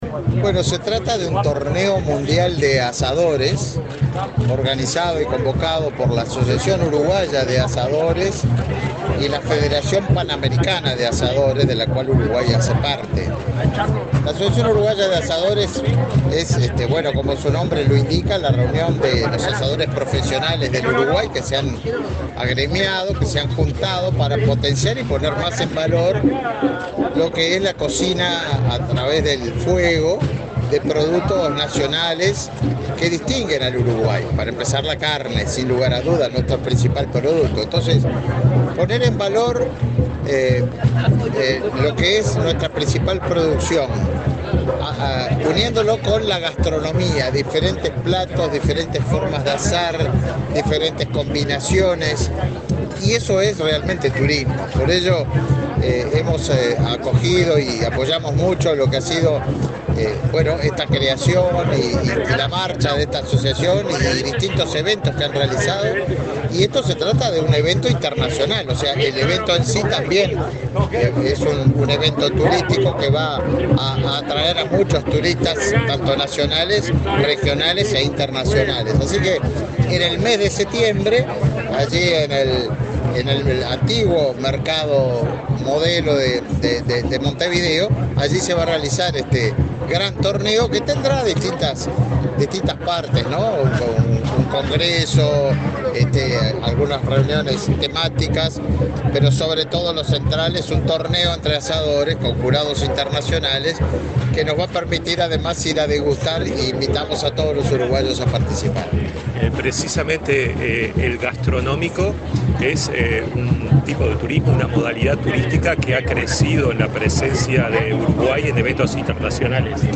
Declaraciones del ministro de Turismo, Tabaré Viera
El ministro de Turismo, Tabaré Viera, dialogó con la prensa, este martes 5 en el Museo del Carnaval de Montevideo, durante la presentación del Mundial